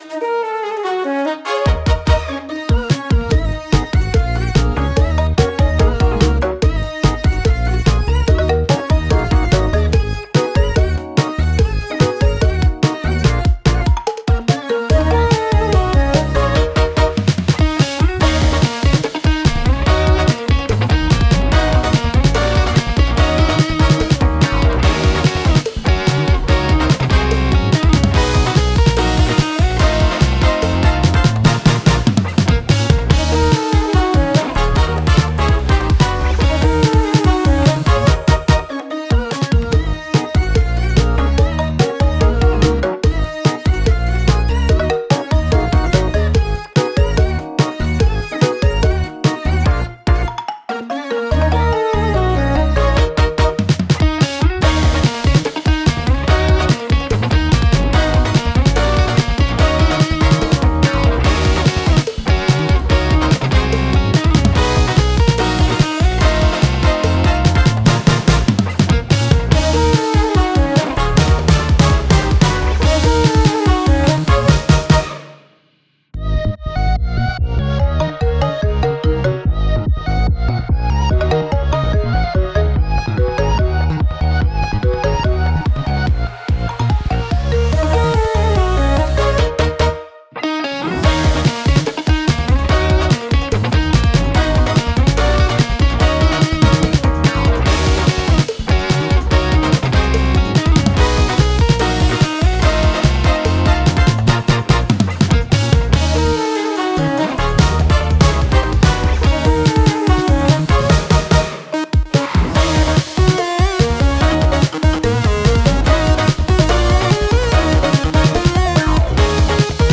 Wow, this remix is awesome !!